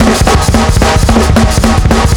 Index of /m8-backup/M8/Samples/musicradar-metal-drum-samples/drums acoustic/220bpm_drums_acoustic